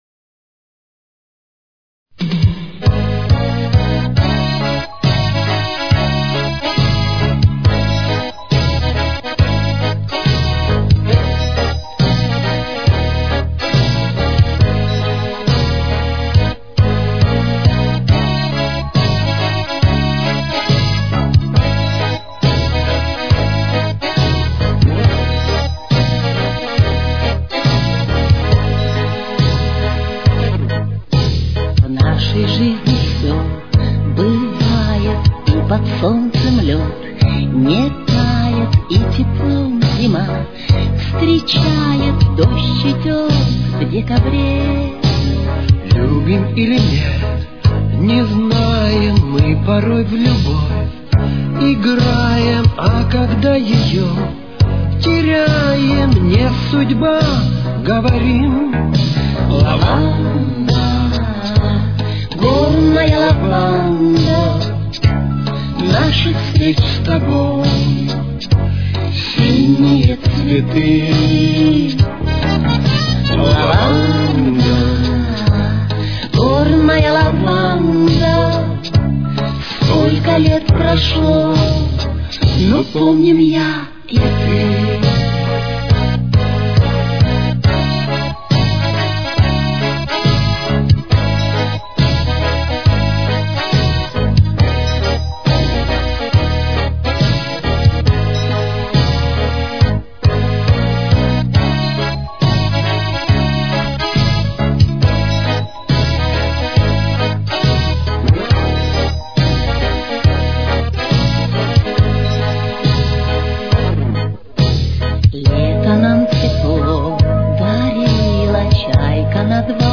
с очень низким качеством (16 – 32 кБит/с)
Соль-диез минор. Темп: 140.